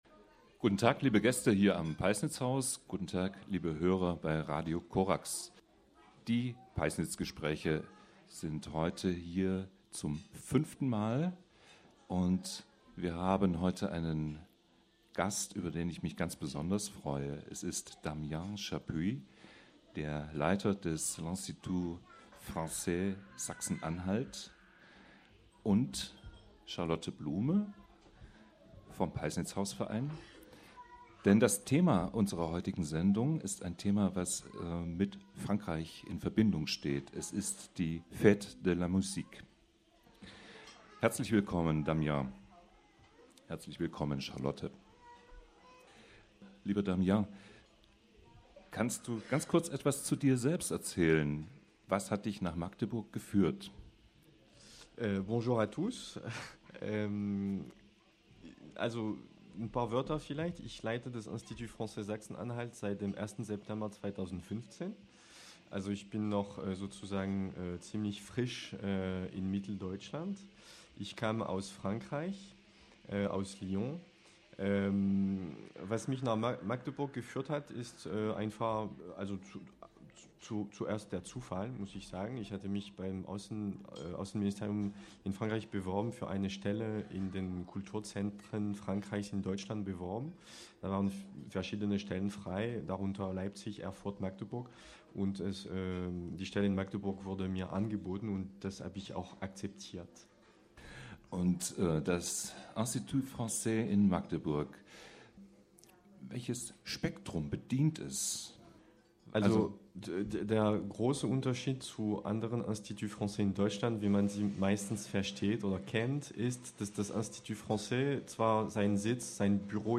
Monatliche Live-Gesprächsrunde am Peißnitzhaus und Radiosendung auf Radio Corax.
Dazu Veranstaltungstipps mit Musik.